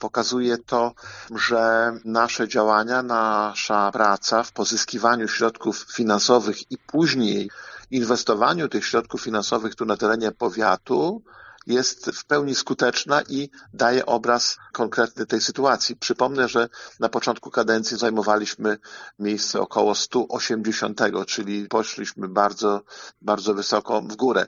O wyniku mówi starosta łomżyński, Lech Marek Szabłowski: